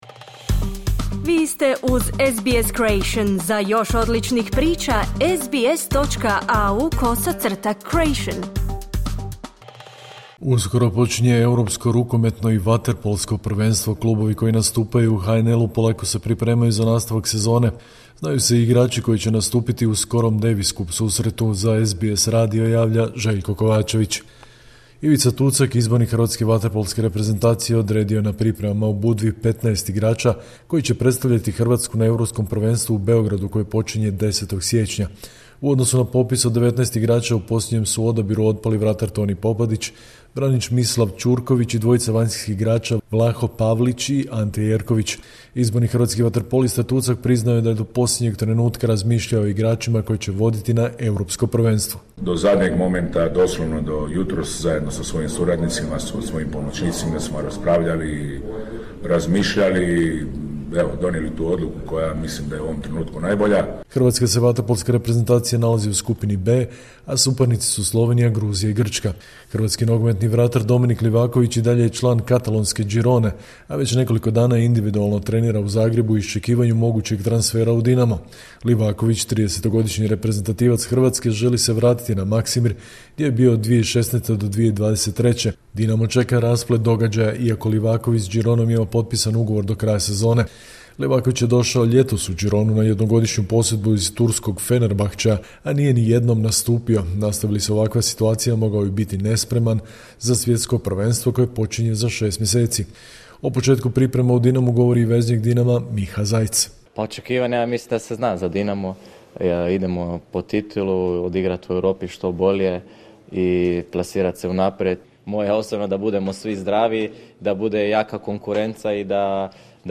Sportske vijesti iz Hrvatske, 8.1.2026.